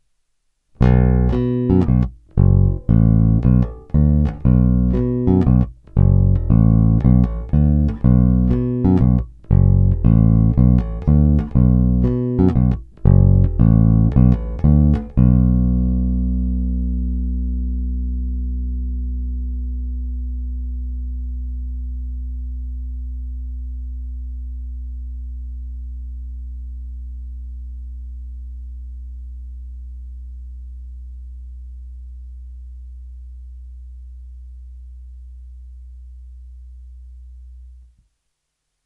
Masívní basy, vrčivé středy, kousavé výšky.
Není-li uvedeno jinak, následující nahrávky jsou nahrány rovnou do zvukovky a dále jen normalizovány.
Hra nad snímačem